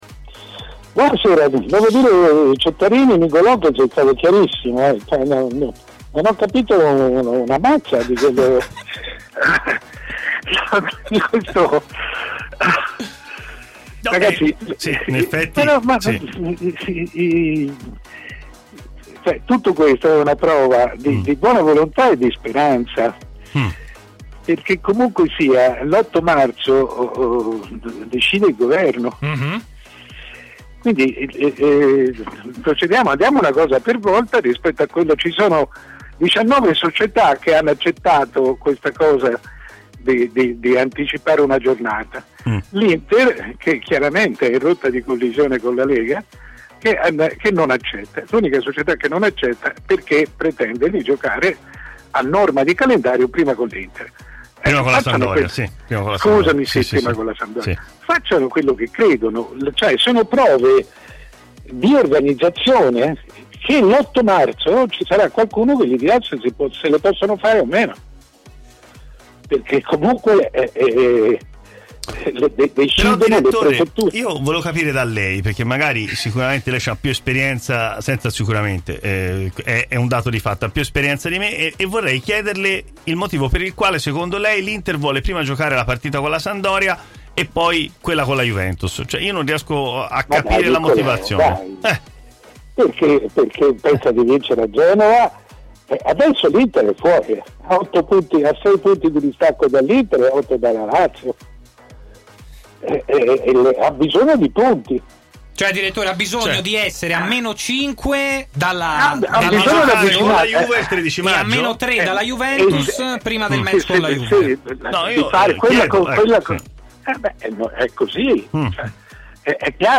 Mario Sconcerti, prima firma del giornalismo italiano, ospite di 'Stadio Aperto' su TMW Radio: